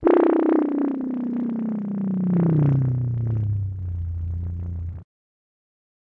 科幻 " 窥视2
描述：使用vst instruments / Public Domain制作 是否归属 自行决定！
标签： 未来 信号 警告 怪异 未来 报警 科学 窥视 飞船 警报 FX 应急 科幻 悬停 发动机 飞船 打电话 数字 声音设计 小说 空间 电子 噪音 外星人 能源 大气
声道立体声